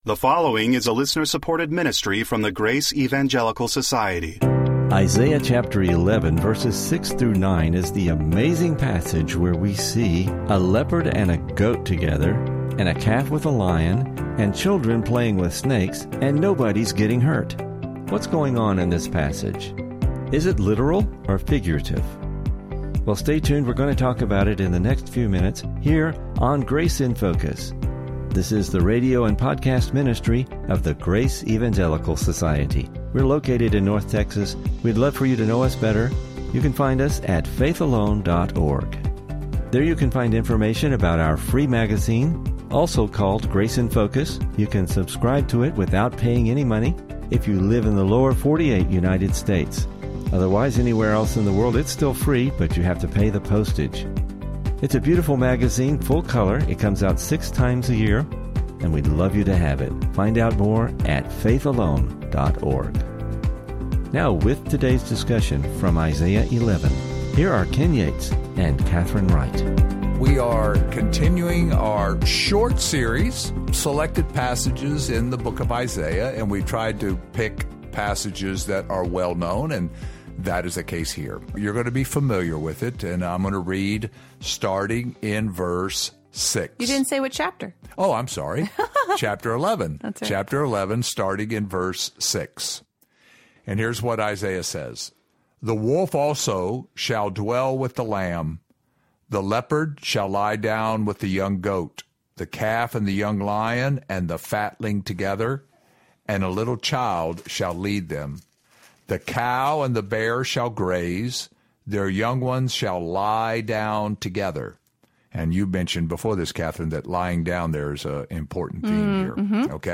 Please listen for an interesting discussion and lessons related to this passage.